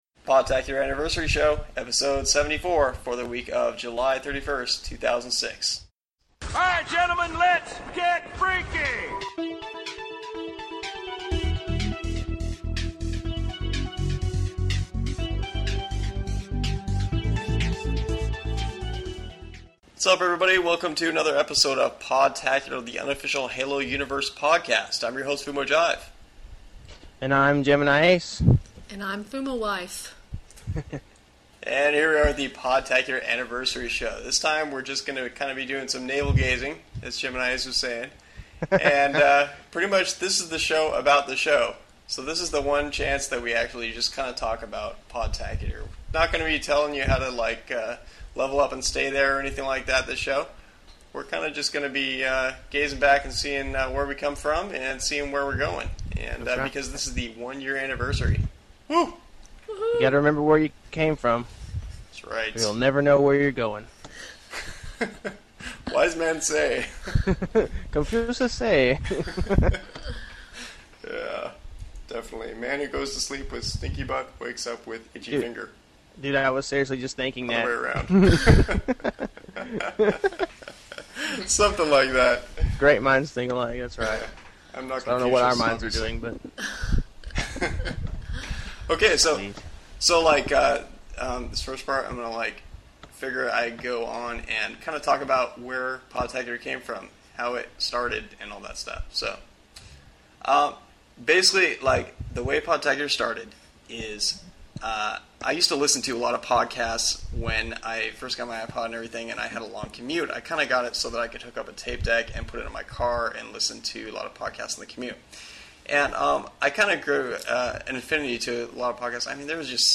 At the end there’s an awesome mashup of some cool moments from past shows.